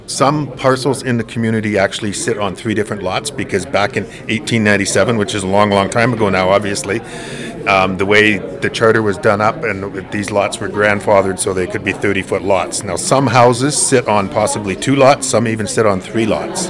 Here’s Councilor Dan Rye.